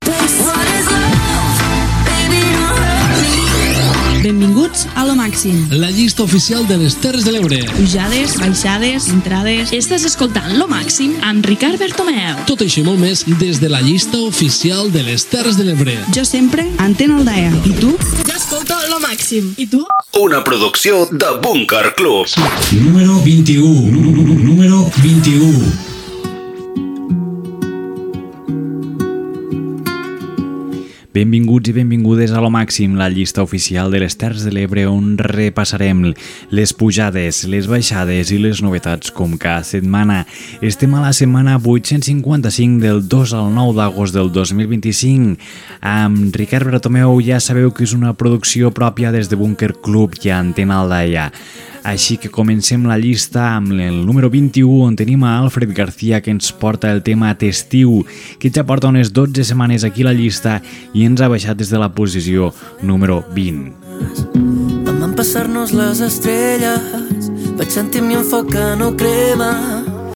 Careta del programa, presentació i tema musical
Musical